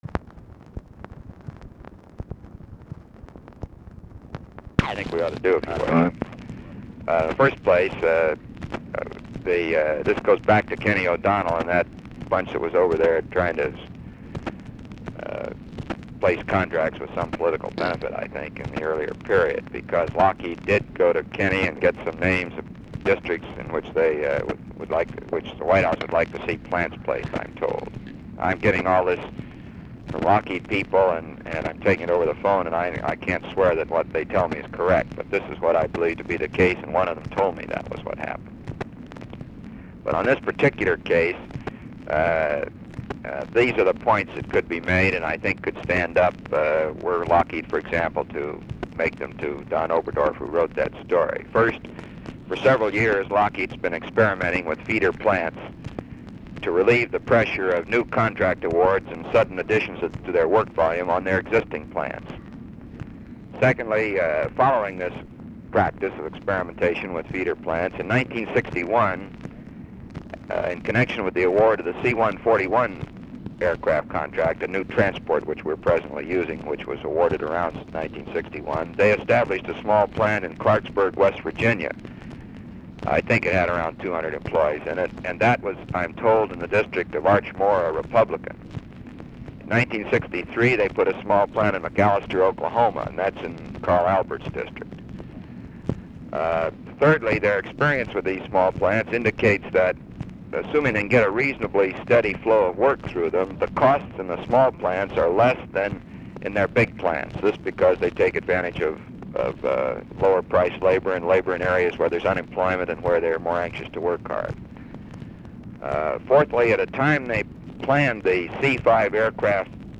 Conversation with ROBERT MCNAMARA, February 18, 1967
Secret White House Tapes